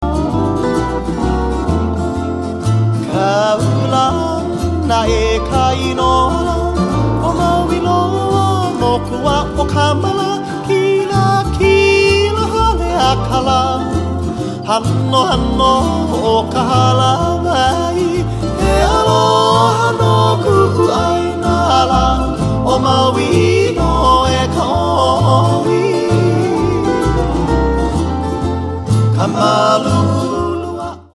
• Genre: Traditional and contemporary Hawaiian.